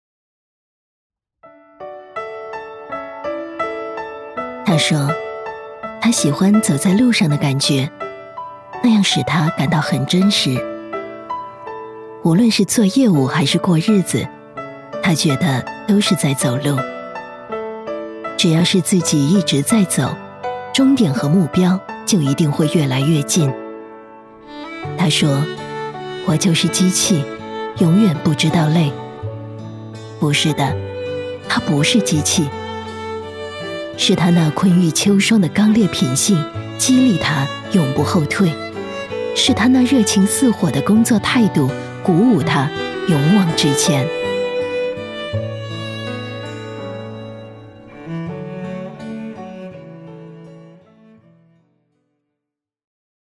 女国163_专题_人物_人物专题.mp3